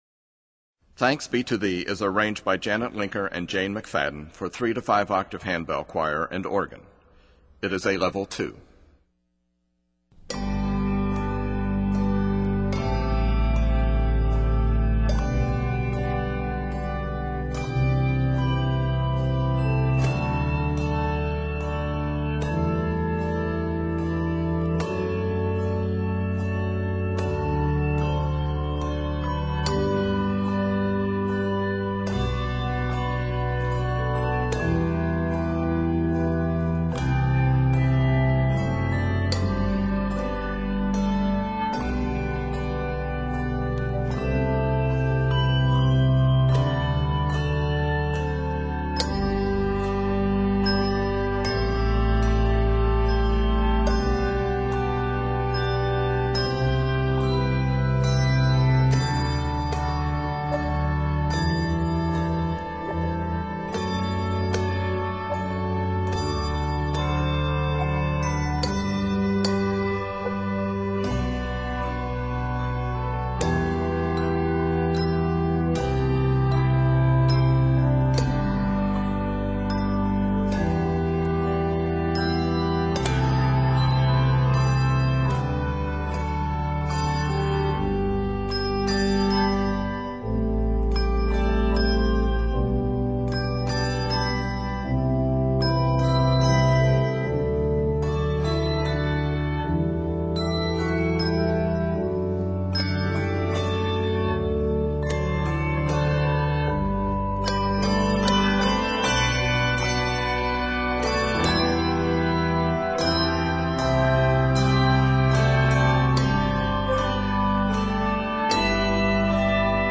Organ and handbells perform this entire arrangement